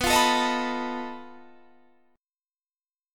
Listen to B7b5 strummed